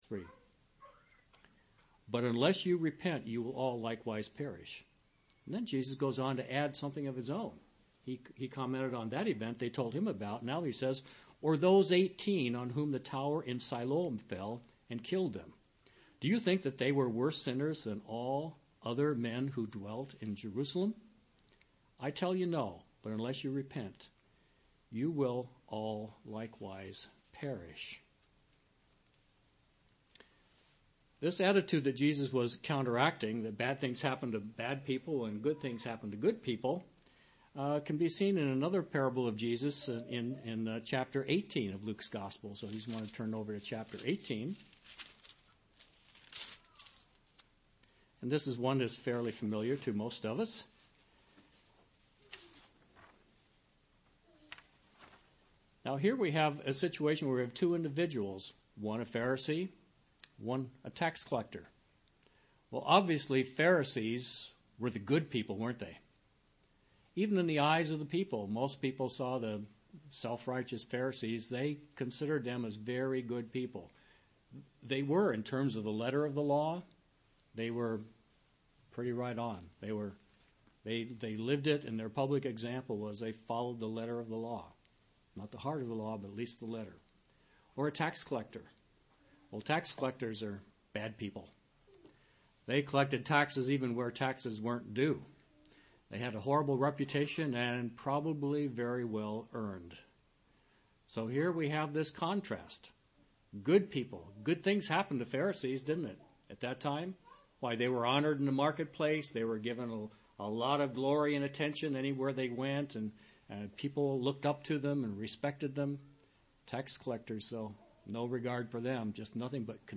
Given in Wheeling, WV
UCG Sermon Studying the bible?